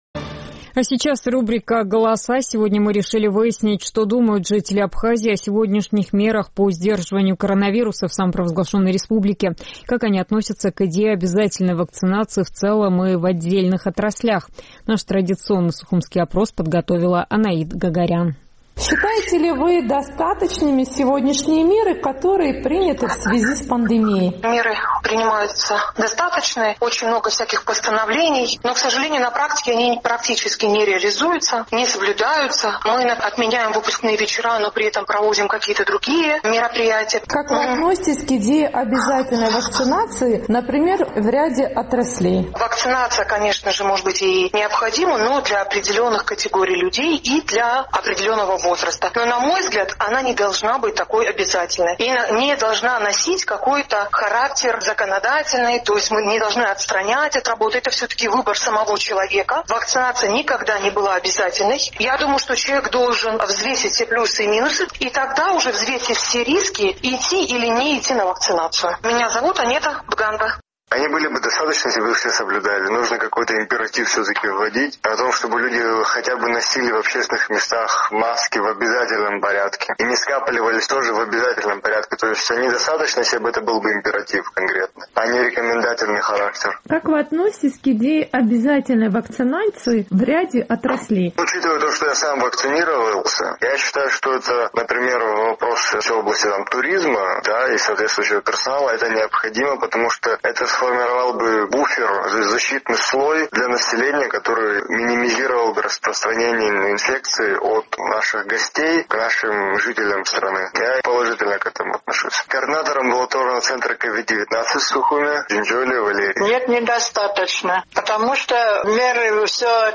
Сегодня «Эхо Кавказа» решило выяснить, что думают жители Абхазии о сегодняшних мерах по сдерживанию COVID-19, и как они относятся к идее обязательной вакцинации в целом или в отдельных отраслях? Наш традиционный сухумский опрос.